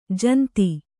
♪ janti